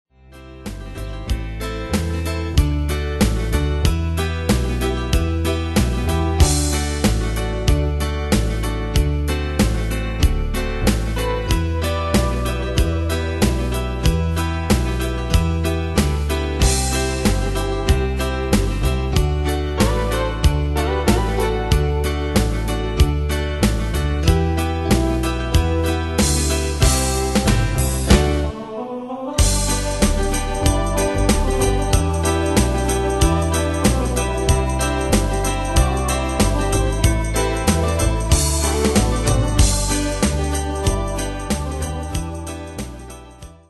Demos Midi Audio
Danse/Dance: Country Cat Id.